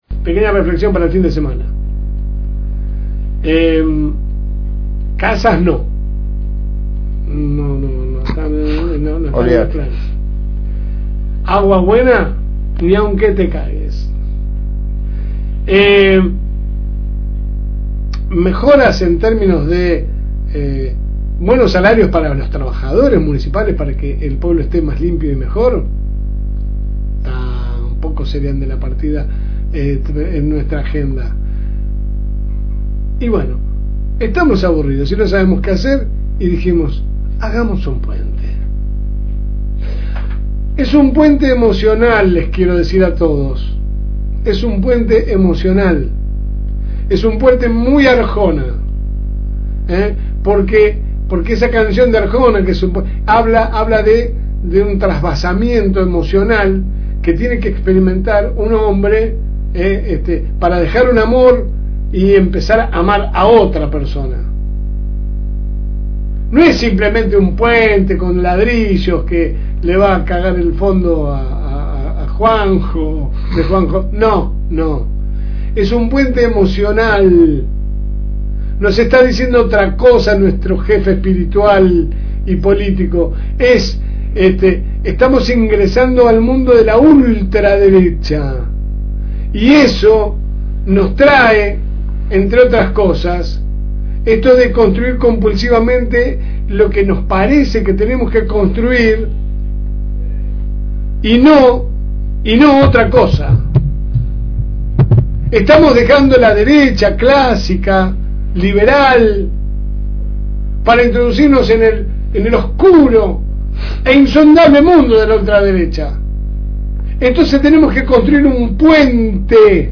Compartimos con ustedes la última editorial de la semana
Su programa sale de lunes a viernes de 10 a 12 HS por el aire de la FM Reencuentro 102.9